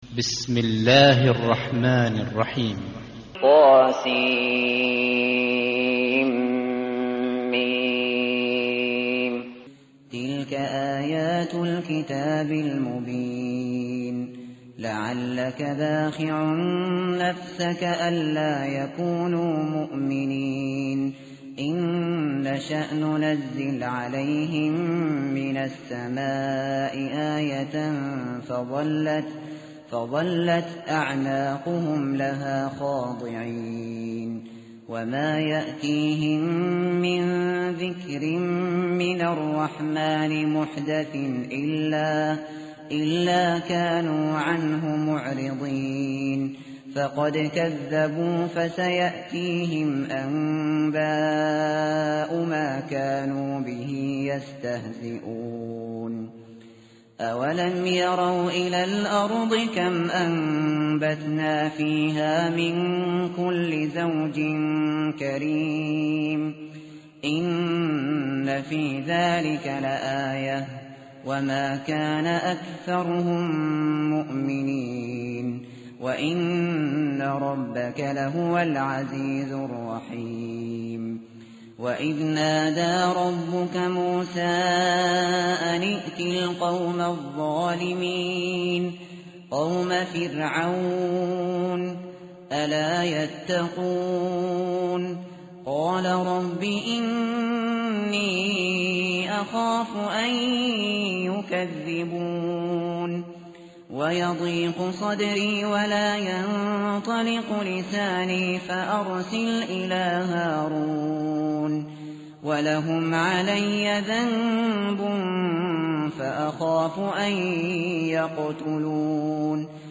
متن قرآن همراه باتلاوت قرآن و ترجمه
tartil_shateri_page_367.mp3